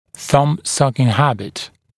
[ˌθʌm’sʌkɪŋ ‘hæbɪt][ˌсам’сакин ‘хэбит]привычка сосания большого пальца